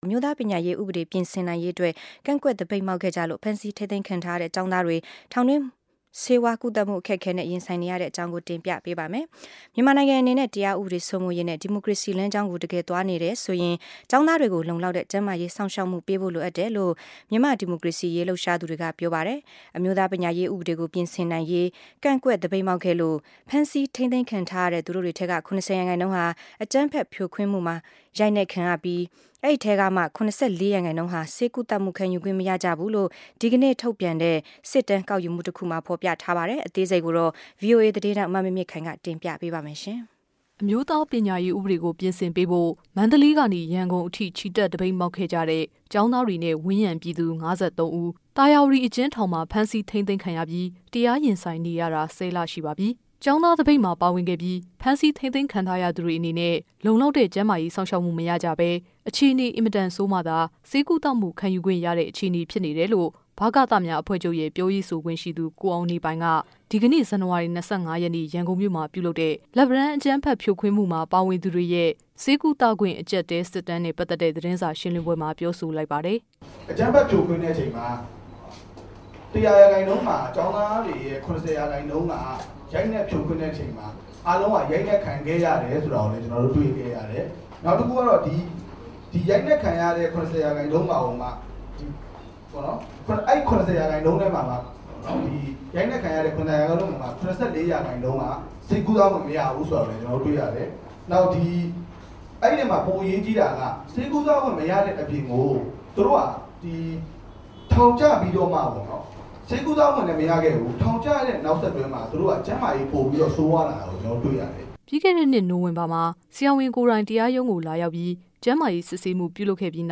လက်ပံတန်း သတင်းစာရှင်းလင်းပွဲ